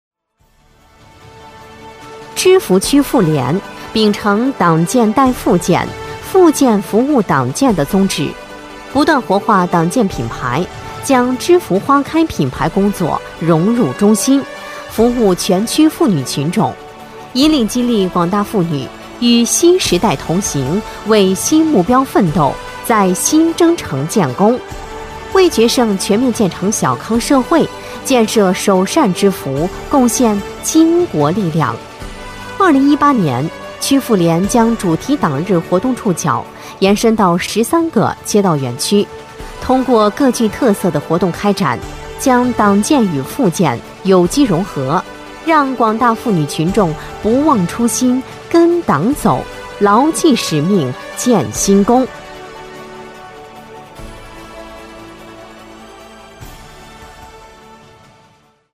女国133_专题_汇报_芝罘区_大气.mp3